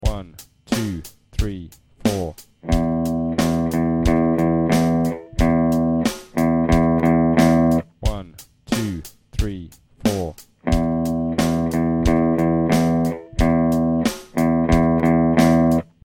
Grade 3 Rhythmic Recall Audio Samples
These are a 2 bar rhythm using the E notes on the 6th string of the guitar.